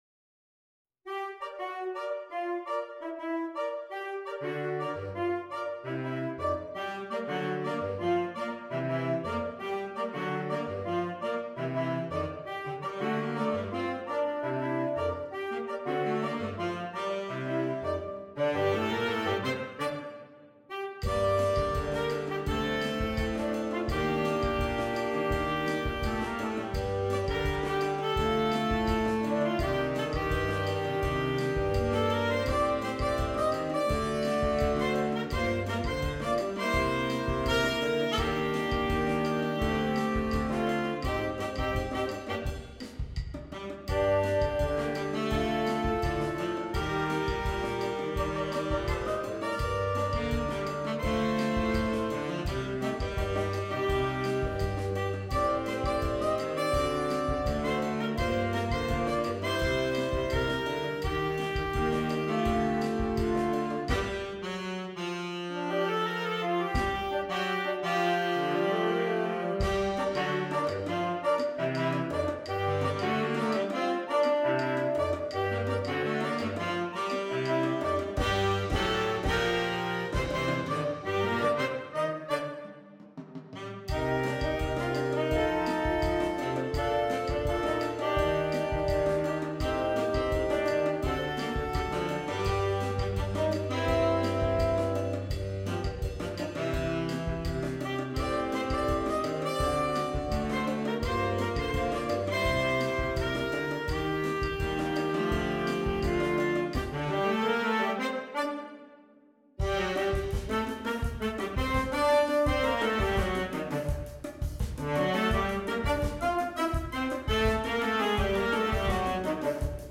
Here is a hot little latin number to spice things up.
• Saxophone Quartet (AATB) and optional Drums